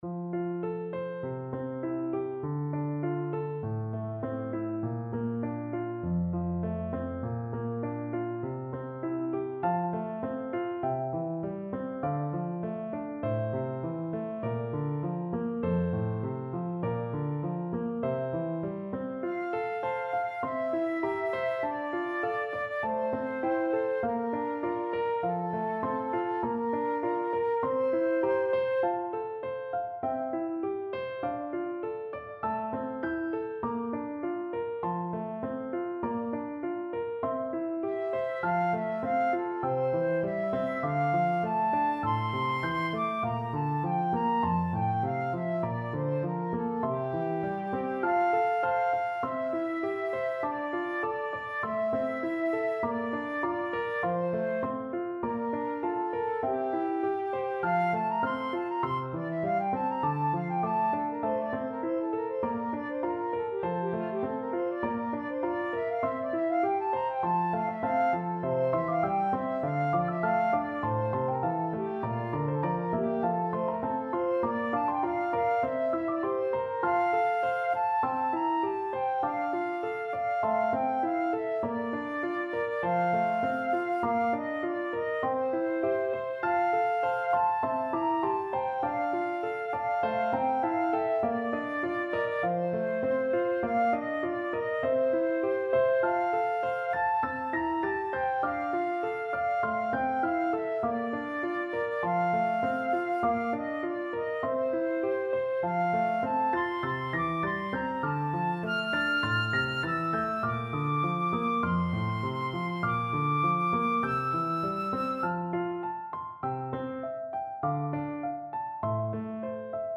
Classical
FluteAlto Saxophone